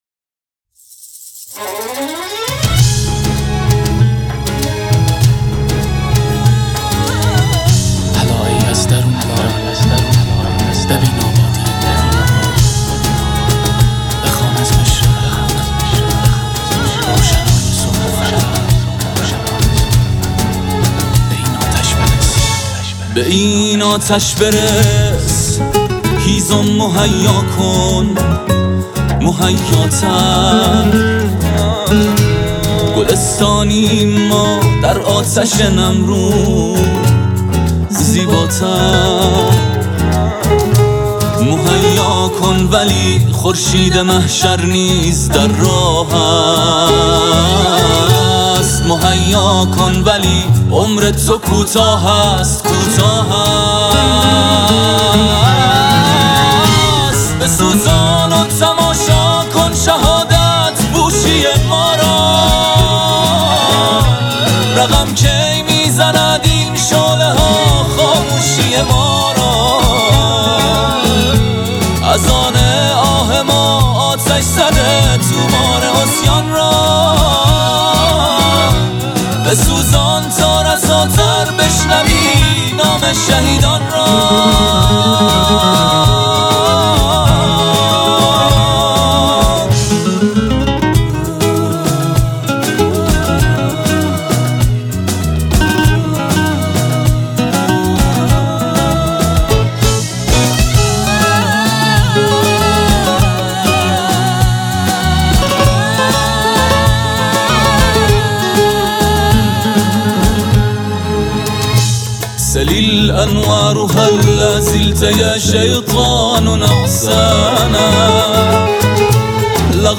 خواننده جوان و خوش آتیه پاپ